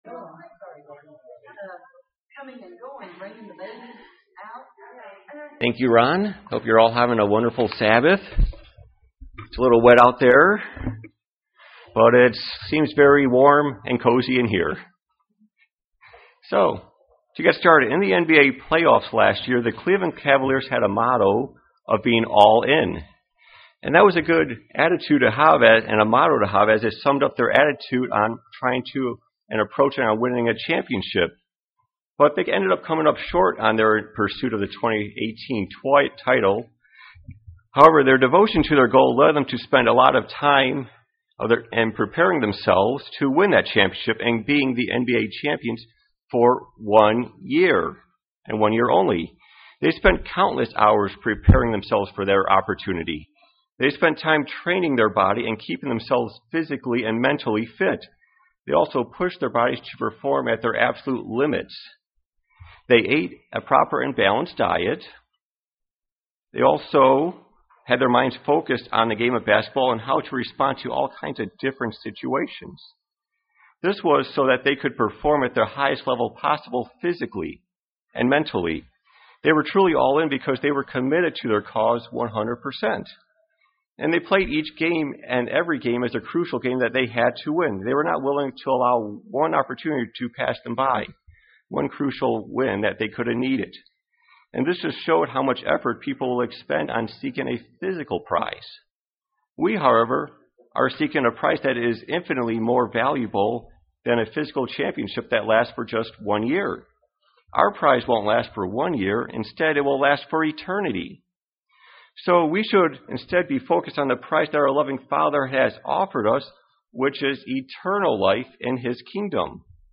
Are You All In On Being In God's Kingdom Sermon?